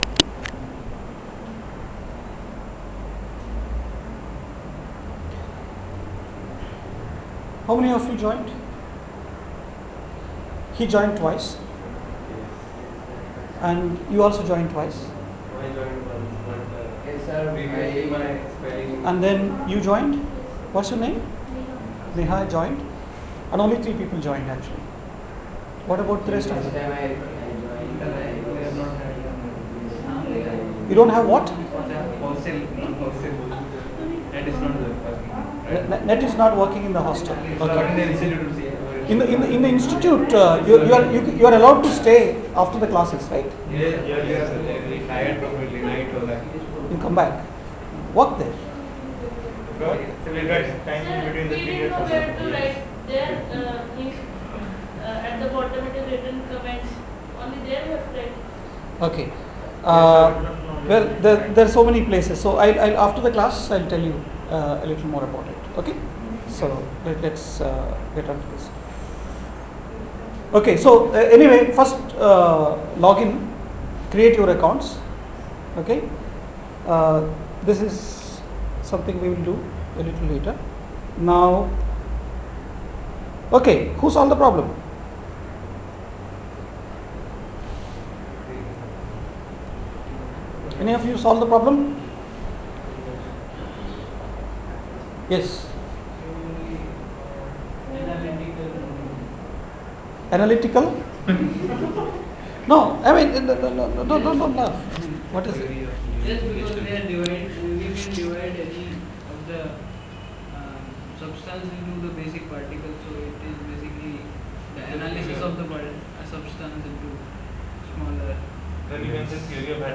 lecture 3